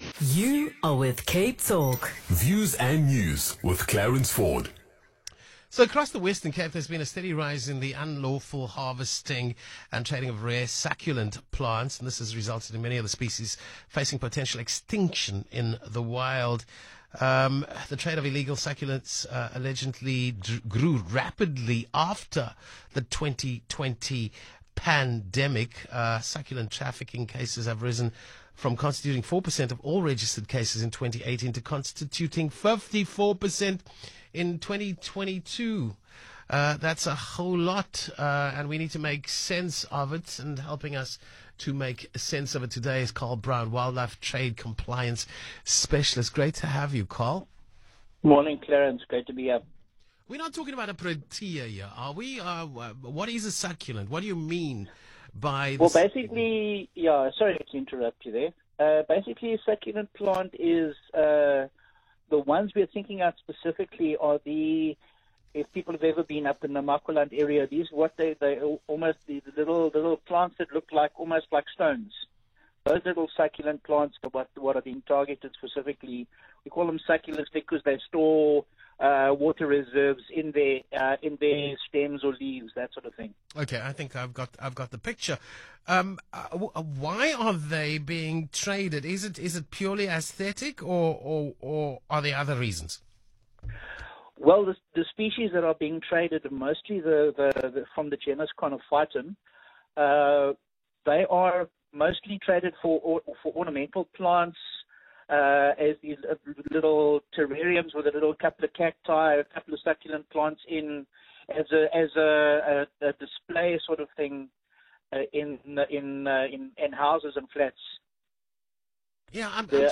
Resources | Biodiversity Crime Radio Interview Cape Talk
Biodiversity-Crime-Radio-Interview.m4a